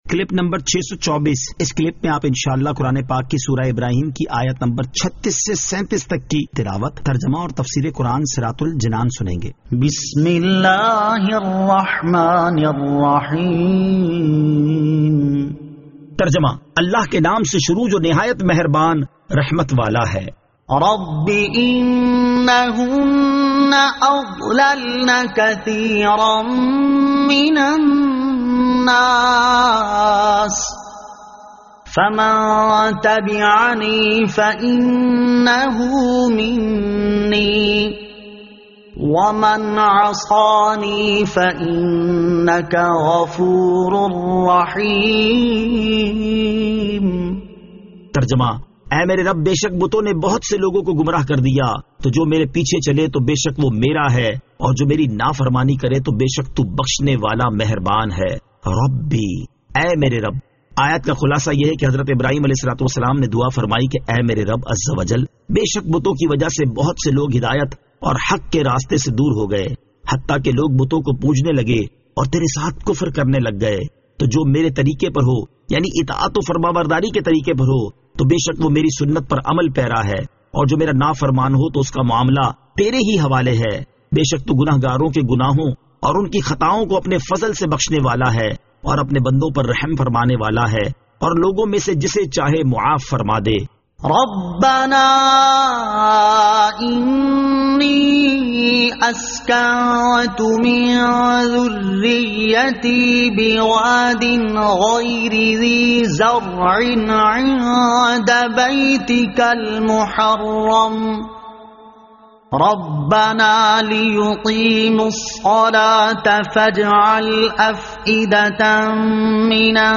Surah Ibrahim Ayat 36 To 37 Tilawat , Tarjama , Tafseer